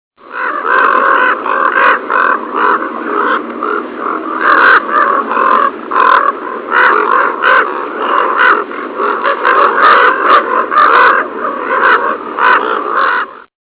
common raven
Here is a sample of their calling en masse.